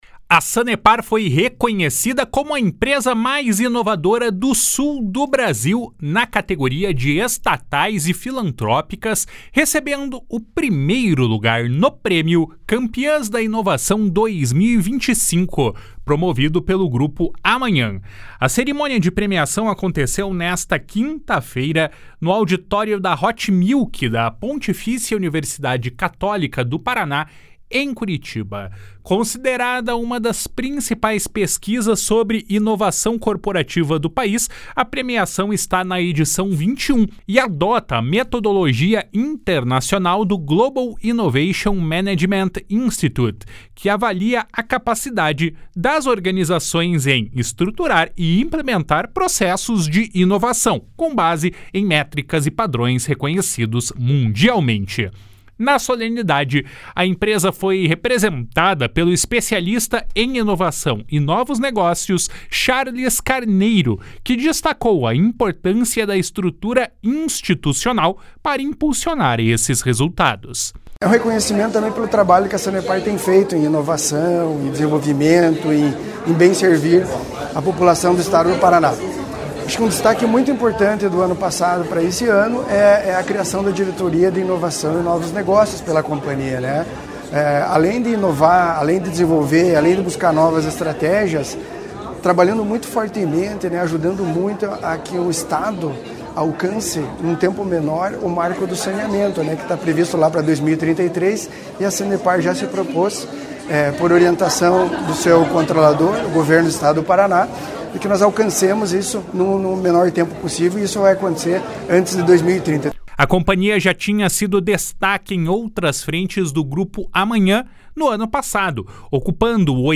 Neste ano, práticas em diversas áreas contribuíram para a conquista, como o fortalecimento do ecossistema de inovação e a adoção de soluções tecnológicas em gestão ambiental. (Repórter